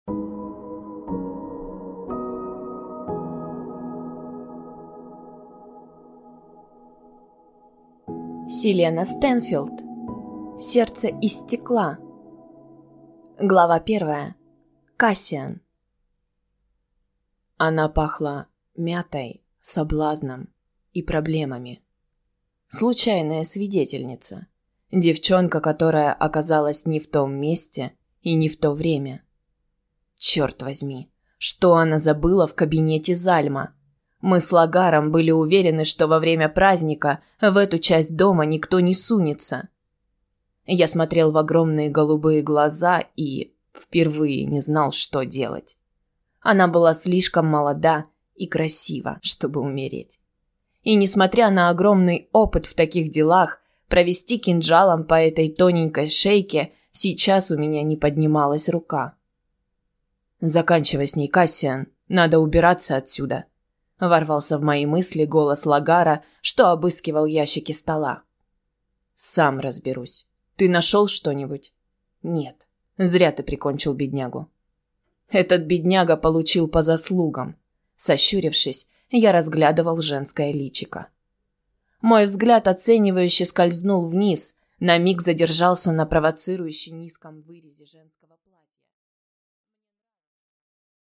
Аудиокнига Сердце из стекла | Библиотека аудиокниг